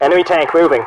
File File history File usage Metadata Ac130_tvo_14_TMP_CR.ogg  (Ogg Vorbis sound file, length 0.9 s, 108 kbps) This file is an audio rip from a(n) Xbox 360 game.